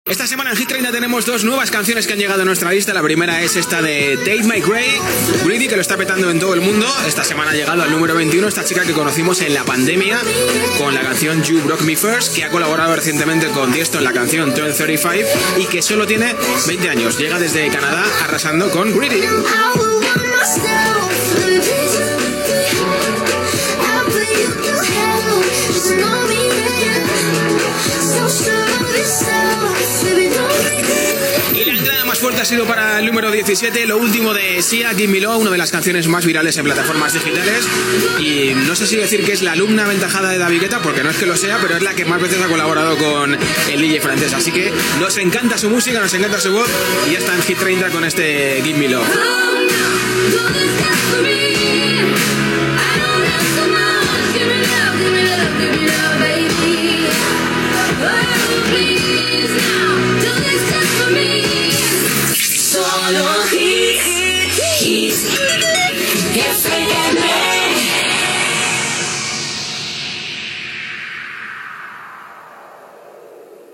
Presentació d'un parell de temes de la llista d'èxits i indicatiu Gènere radiofònic Musical